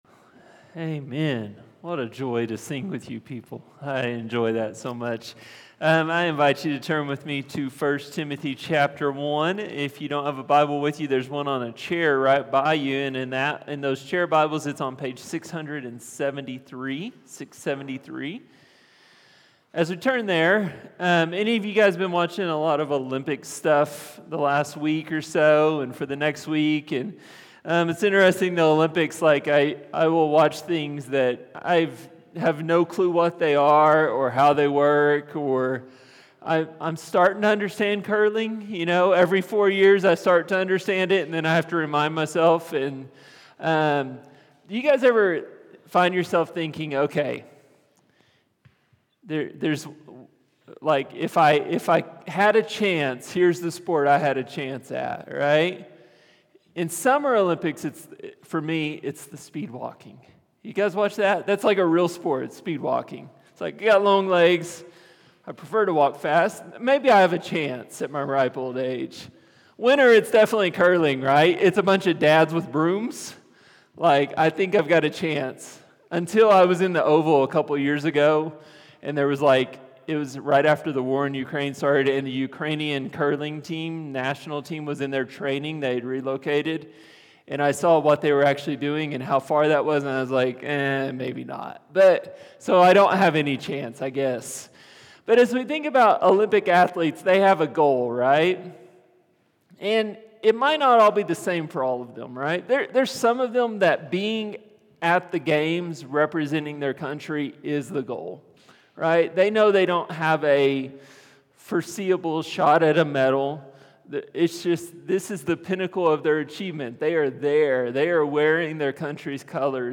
A message from the series "1 Timothy."